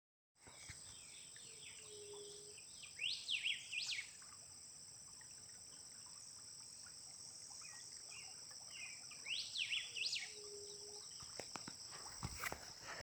Golden-billed Saltator (Saltator aurantiirostris)
Detailed location: Aldea San Gregorio
Condition: Wild
Certainty: Observed, Recorded vocal